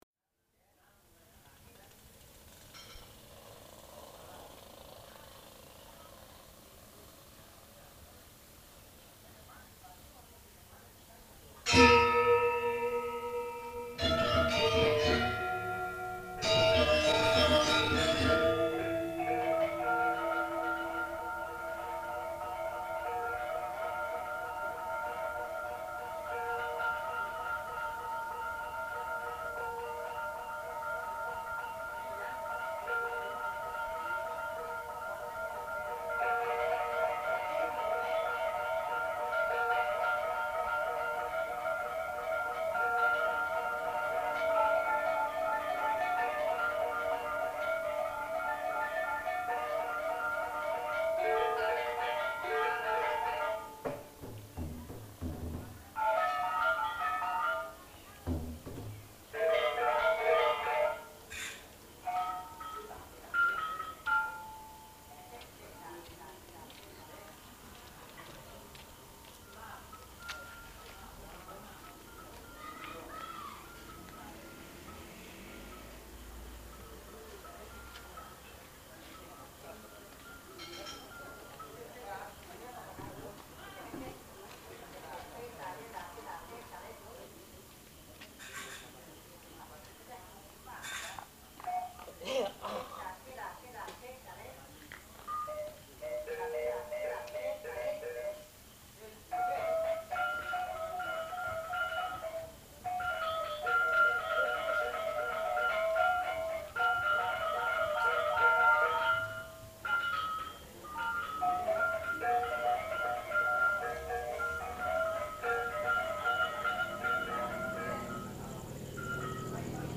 1990 … Balinese evening soundscape
1990 … Evening junior gamelan practice, Yamaha and barking night dogs
Bali’s barking night dogs … 3.5 mins
Walking home from the incredible Ubud night market [ sadly no longer in existence ] — as I arrived at the Banjar Kalah Corner just past Peliatan, I heard the sounds presented here.
Continuing down the track towards the rice paddies and my losmen, a passing Yamaha set the night dogs barking … and as the bike had gone they chose me to bark at till I arrived at my losmen.
r09-3_evening-junior-gamelan-practice-peliatan-banjar-passing-bike-many-barking-dogs4-5mins.mp3